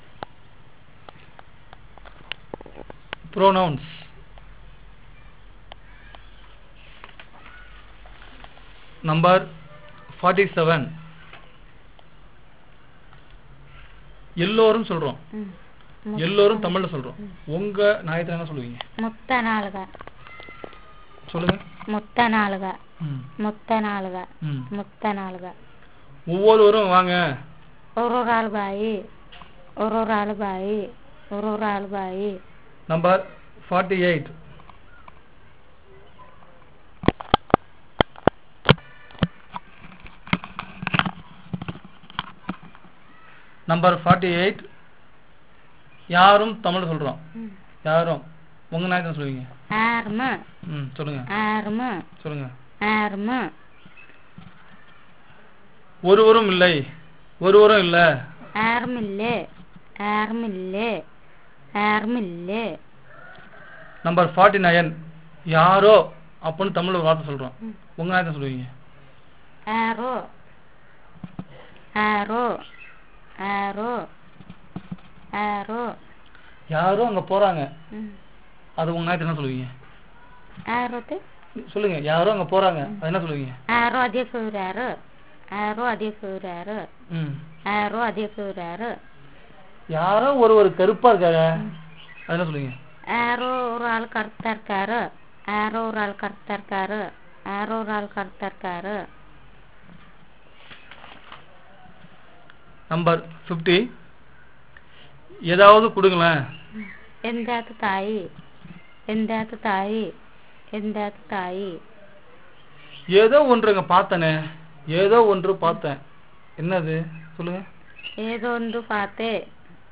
Elicitation of words for pronouns
The RP is collecting certain terms that were not taken in the previous elicitation sessions. The pronouns are quantifiers.